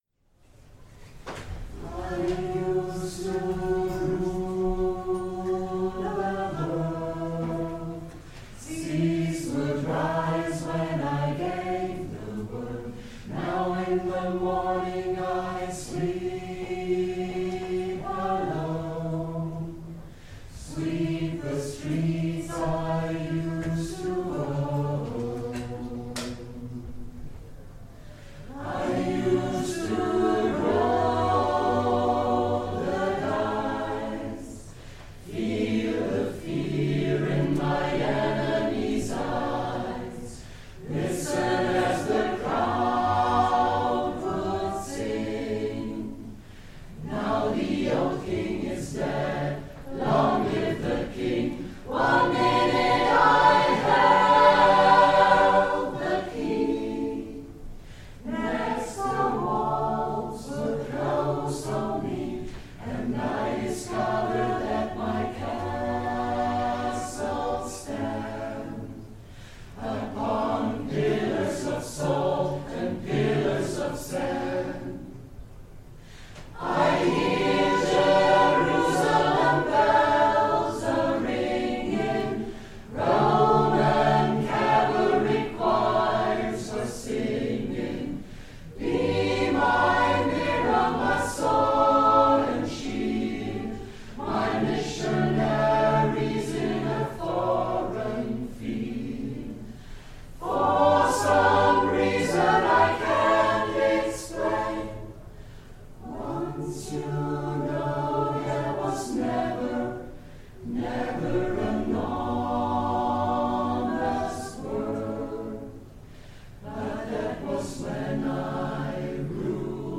Archivmitschnitt "Viva la Vida" und "Mu Ruoktu"
Als Weihnachtsgeschenk habe ich für Euch zwei alte Aufnahmen von Voice Affair von 2019 ausgegraben - das war in der Linse Weingarten und in der Mühle Oberteuringen. Dieses Jahr kam dieses Lied in den Weihnachtskonzerten wieder dran - als Zugabe haben wir das Publikum singend von allen Seiten umrundet und klanglich "eingehüllt".